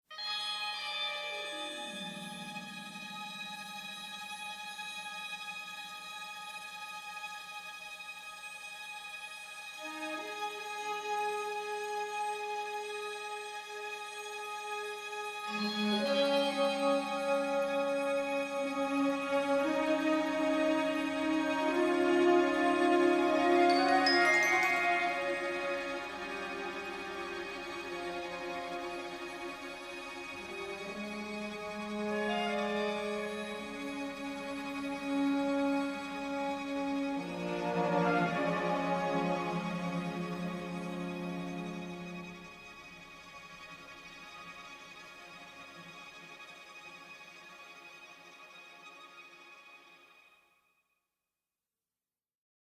virtuoso violin soloist